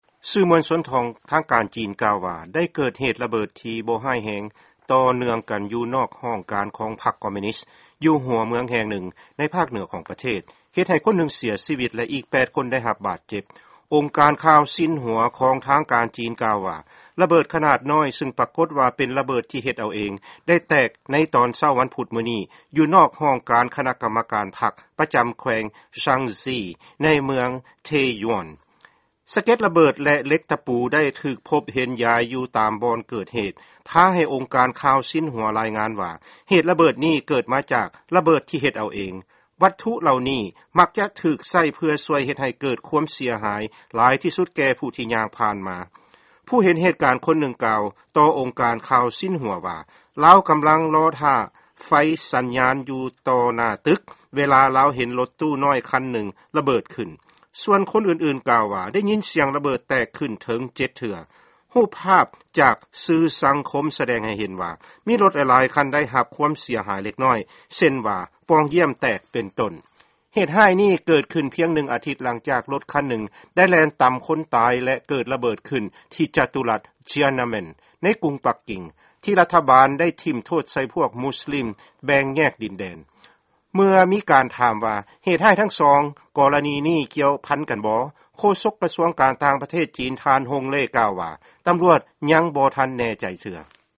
ຟັງຂ່າວ ເຫດລະເບີດແຕກ ທີ່ປະເທດຈີນ